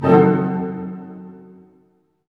Index of /90_sSampleCDs/Roland LCDP08 Symphony Orchestra/HIT_Dynamic Orch/HIT_Tutti Hits